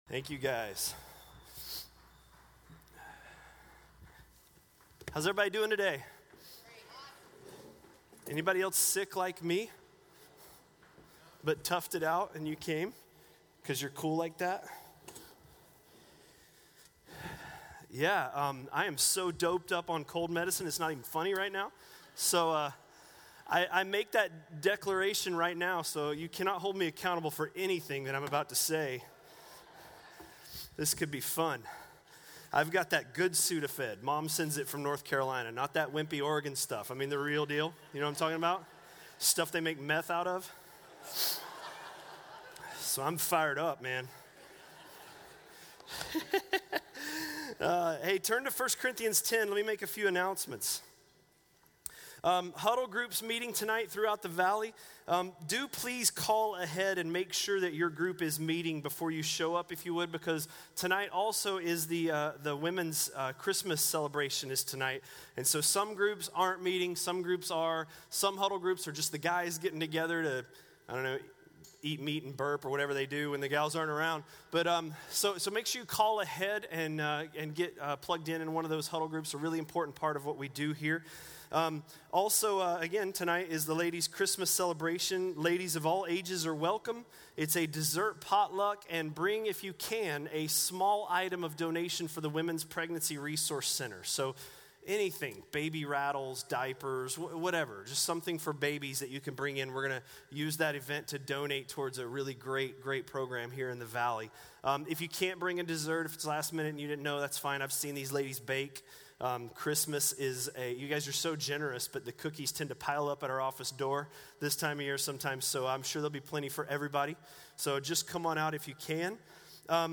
A message from the series "1 Corinthians." 1 Corinthians 10:22–11:1